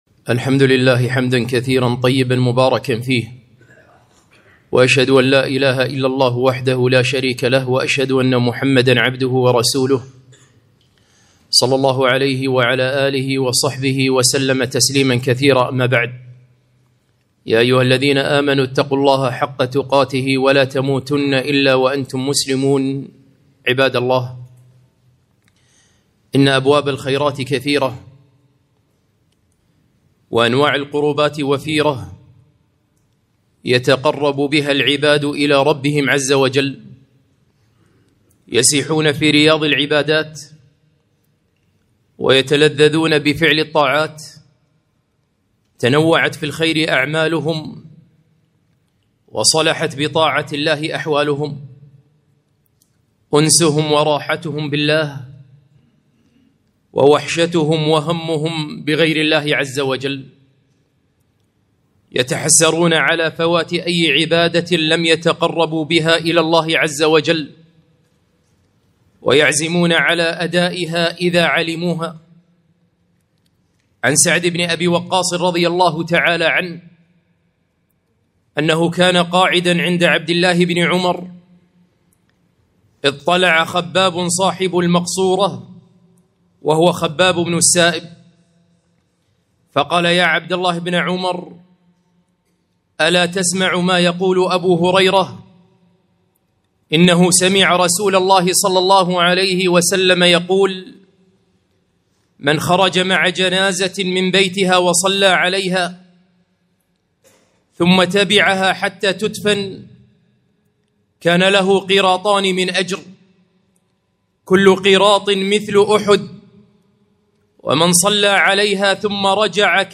خطبة - كم فرطنا في خيرات عظام؟!